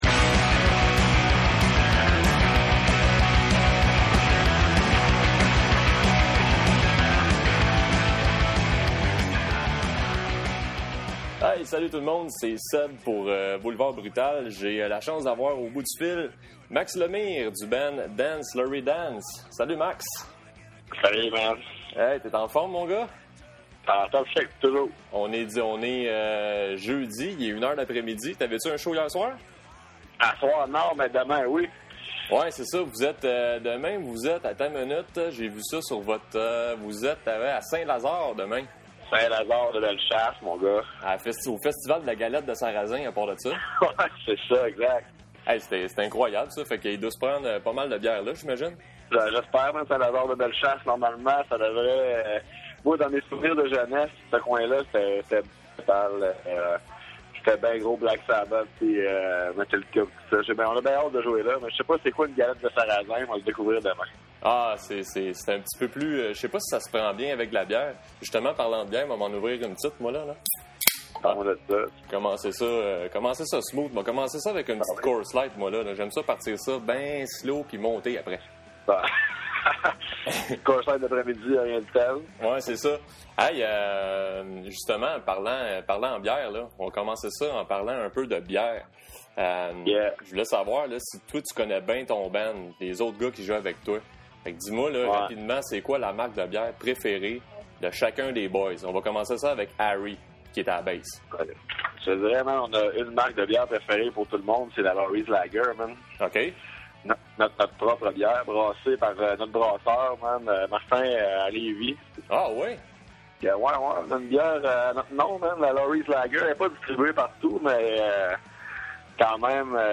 dancelaurydance_interview.mp3